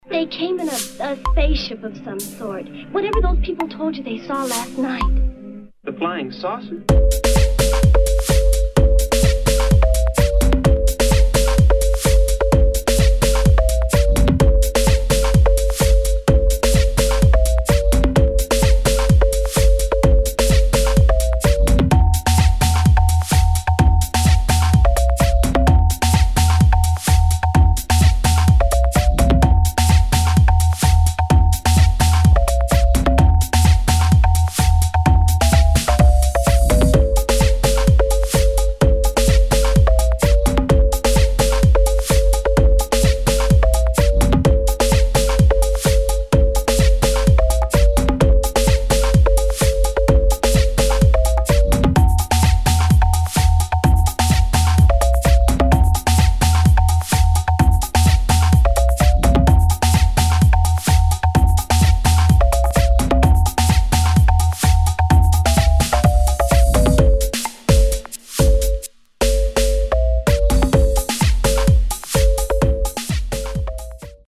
[ UK GARAGE | BASS ]